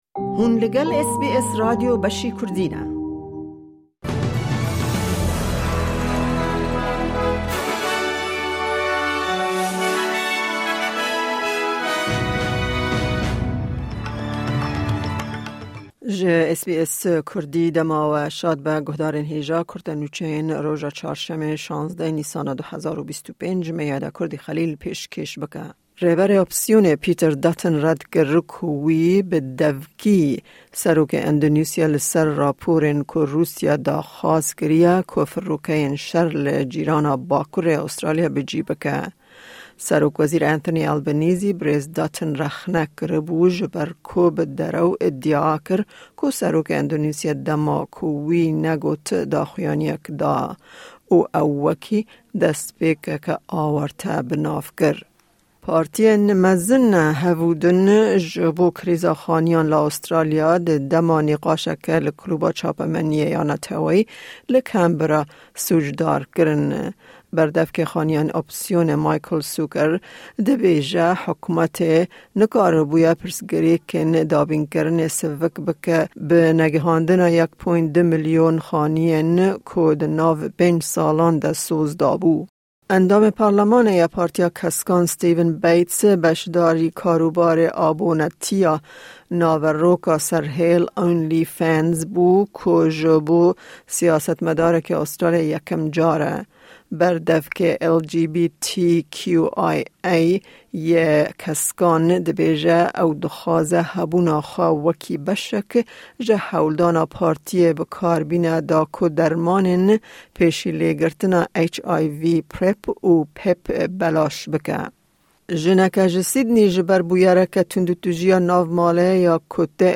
Kurte Nûçeyên roja Çarşemê, 16î Nîsana 2025